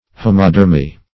Search Result for " homodermy" : The Collaborative International Dictionary of English v.0.48: Homodermy \Ho"mo*der`my\, n. [Homo- + -derm.]
homodermy.mp3